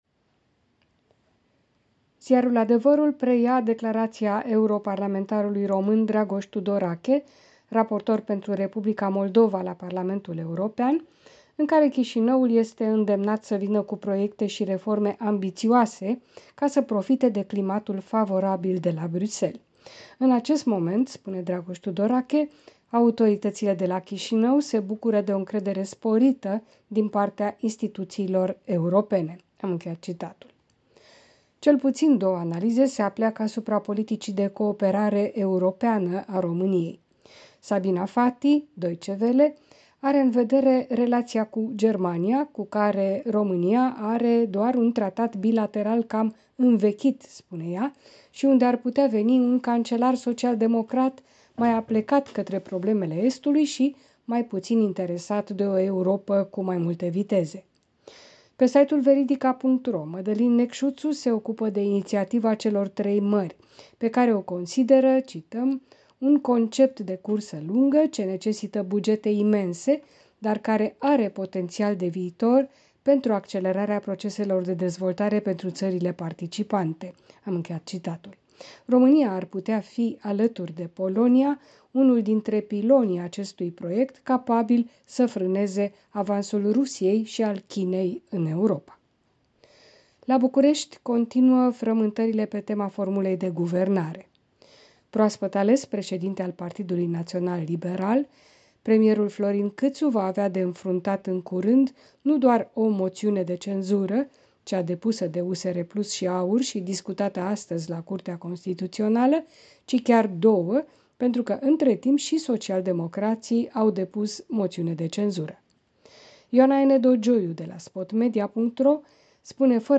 Revista presei de la București.